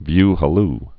(vy hə-l)